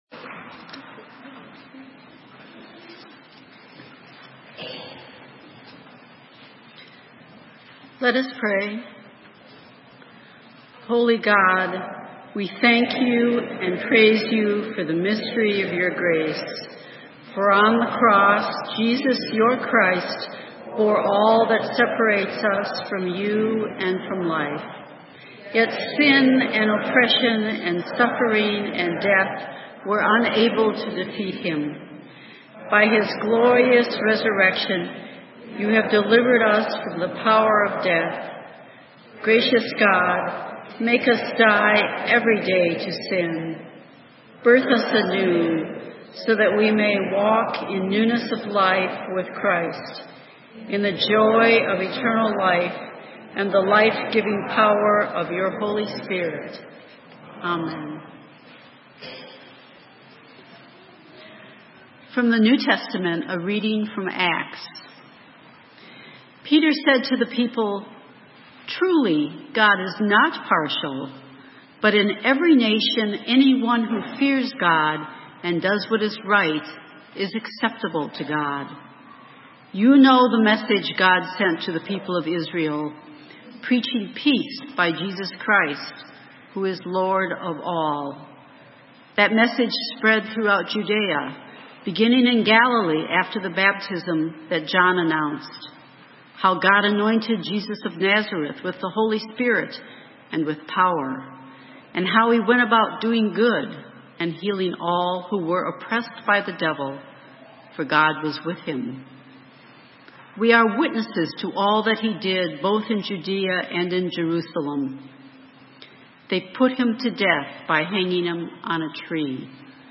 Sermon: The tomb is always empty - Saint Matthew's UMC Acton, MA